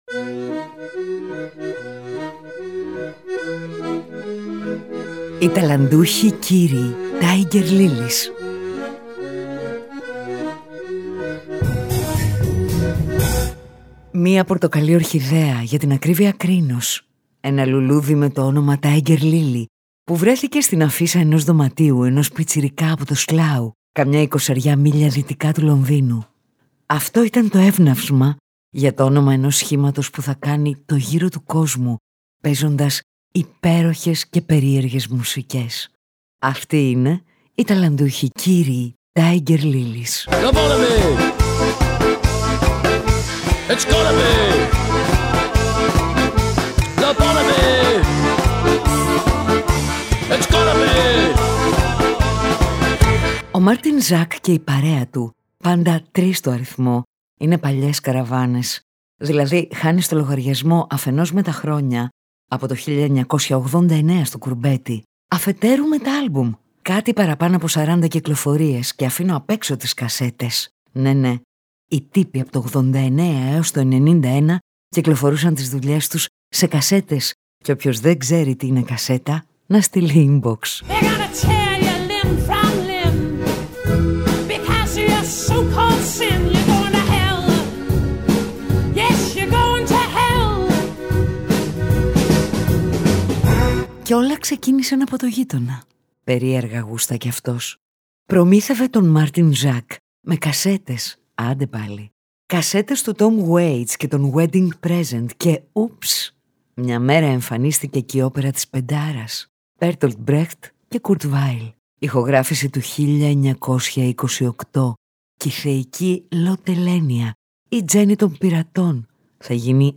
Τραγούδια για εφιάλτες που πλανώνται σα δίνη, πυρκαγιές που πέφτουν σαν κατάρες, λοβοτομές και γυναικείους κόλπους ανεξέλεγκτης ηδονής σ’ έναν συνδυασμό γαλλικού Σανσόν, μπρεχτικής όπερας, βαριετέ και cult καμπαρέ….